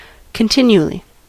Ääntäminen
Synonyymit forever ay every five minutes Ääntäminen US Haettu sana löytyi näillä lähdekielillä: englanti Käännös Adverbit 1. jatkuvasti Määritelmät Adverbit In a continuous manner; non-stop .